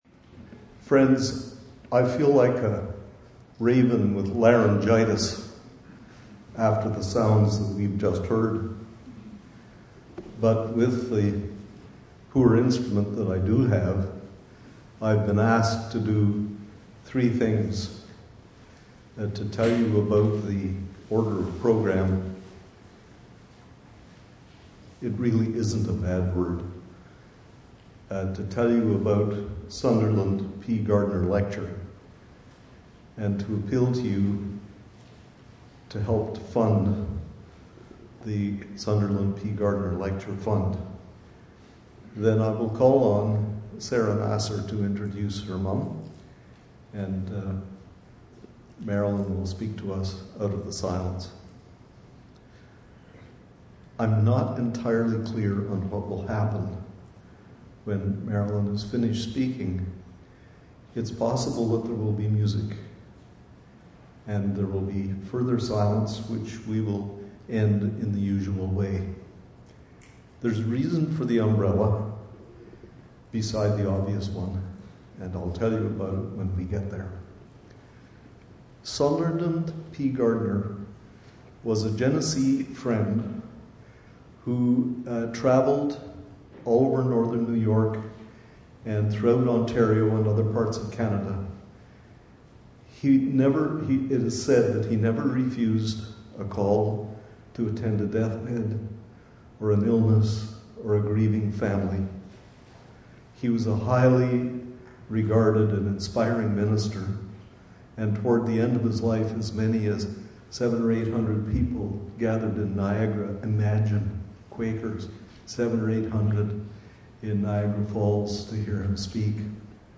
lecture
to a full house at the Fountain Performing Arts Centre at King’s Edgehill School